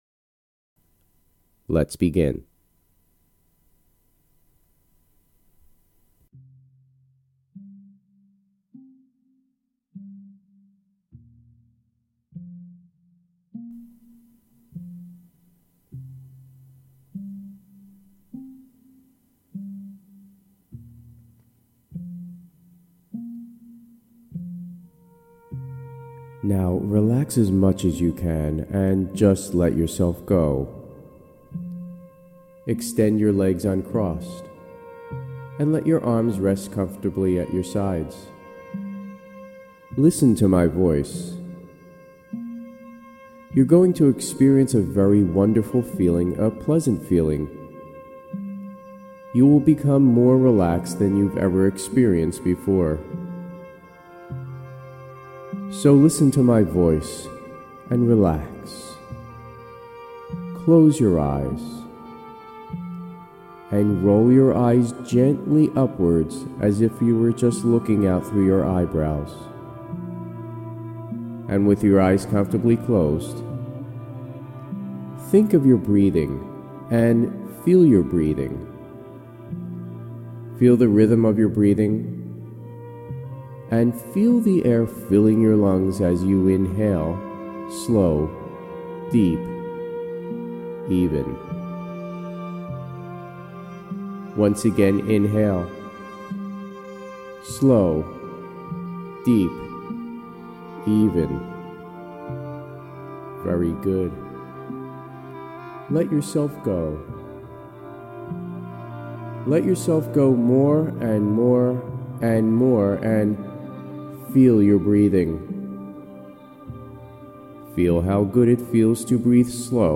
Putt Like A Pro Self-Hypnosis Audio Program